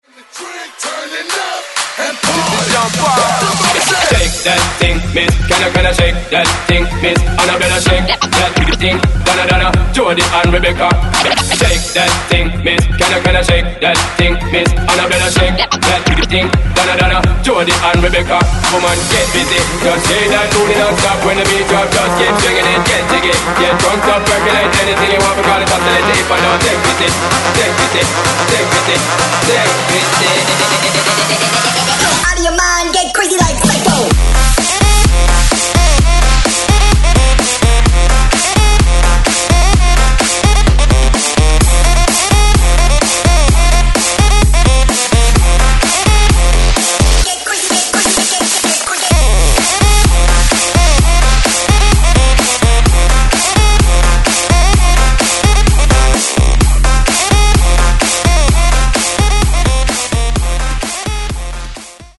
Genre: 2000's Version: Clean BPM: 130 Time